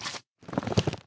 jump4.ogg